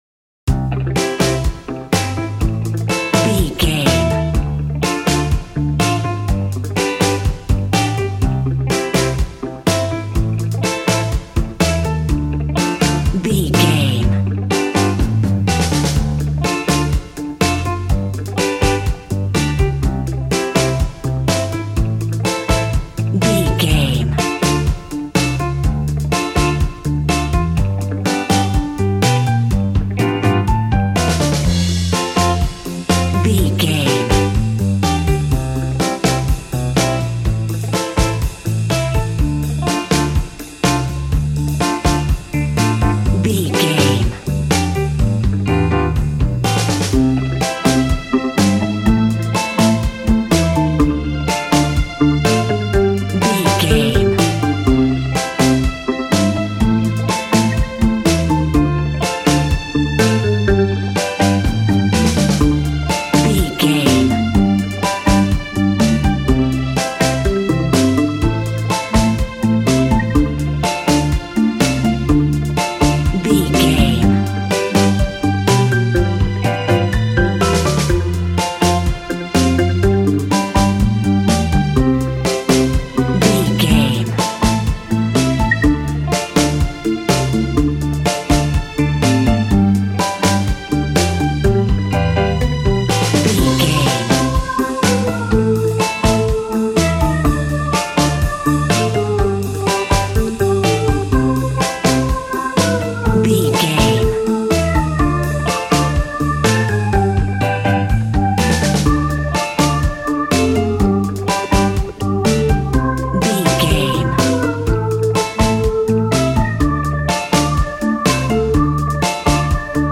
Ionian/Major
cheerful/happy
drums
piano
double bass